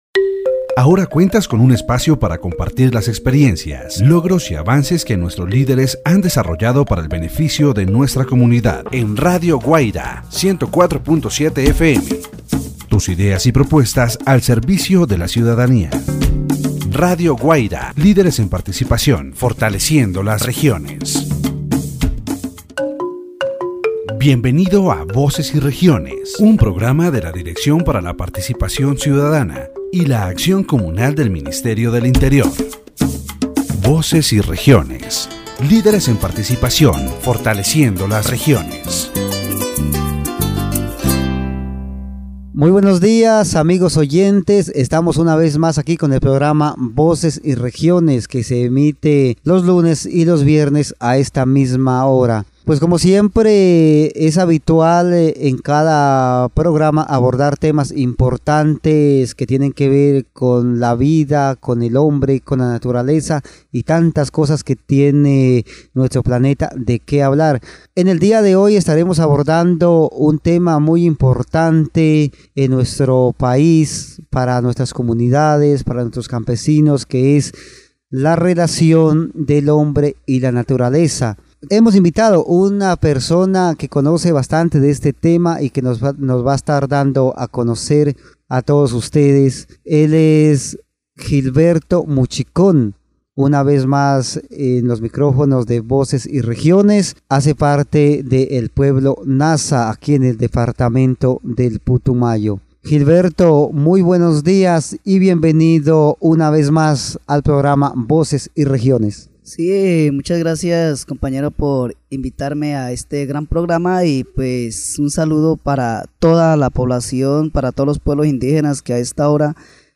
The radio program "Voces y Regiones" on Radio Guaida 104.7 FM, run by the Ministry of the Interior, addresses the relationship between man and nature, highlighting the importance of protecting and preserving the environment from the perspective of indigenous communities.